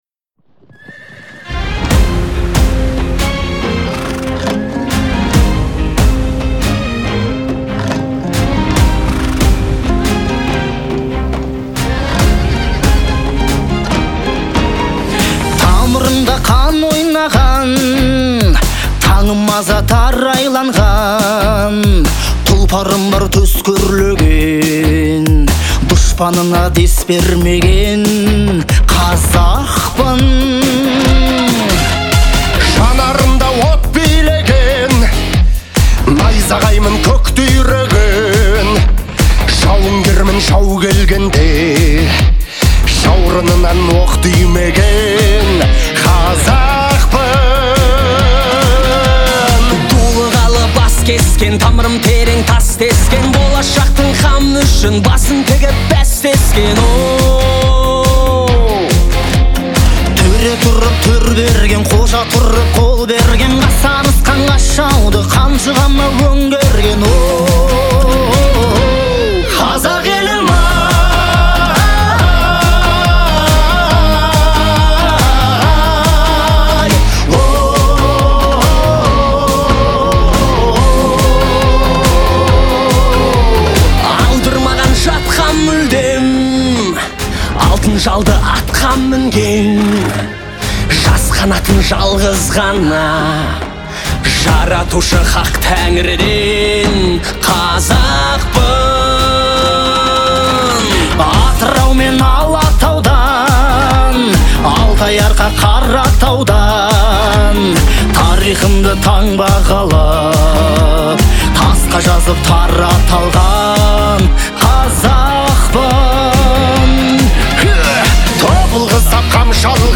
это яркий пример казахского поп-рока